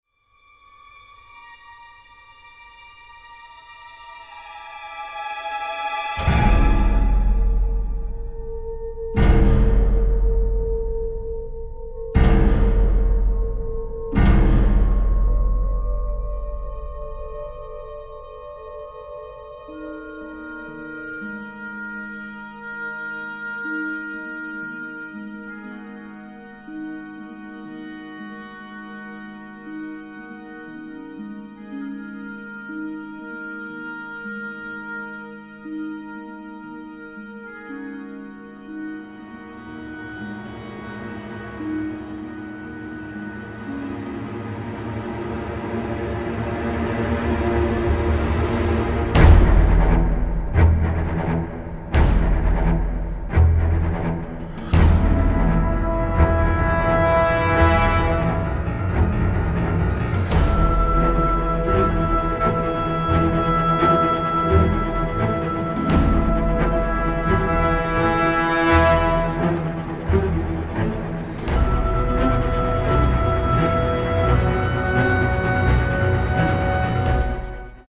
an energetic, dark and crude orchestral score